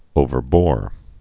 (ōvər-bôr)